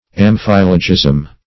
Meaning of amphilogism. amphilogism synonyms, pronunciation, spelling and more from Free Dictionary.
Search Result for " amphilogism" : The Collaborative International Dictionary of English v.0.48: Amphilogism \Am*phil"o*gism\, Amphilogy \Am*phil"o*gy\, n. [Gr.